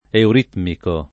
euritmico [ eur & tmiko ] agg.; pl. m. ‑ci